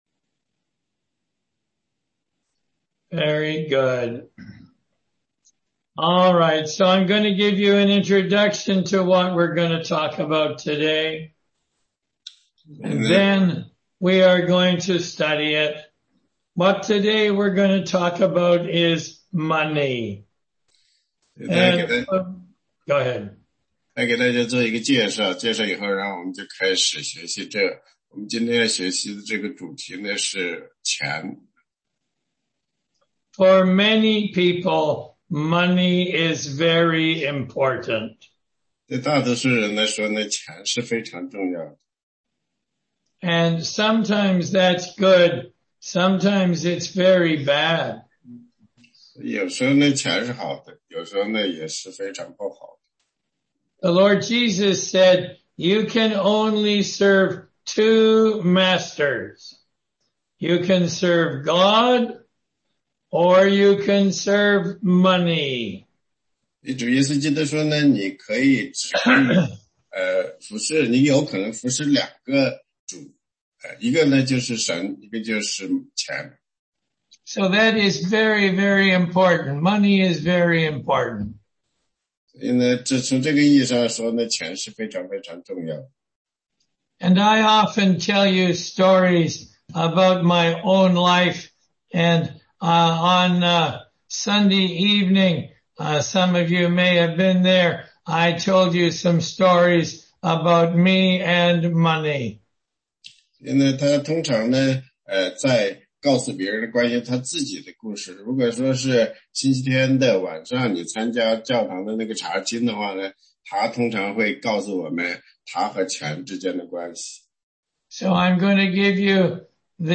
16街讲道录音 - 提摩太前书6章
答疑课程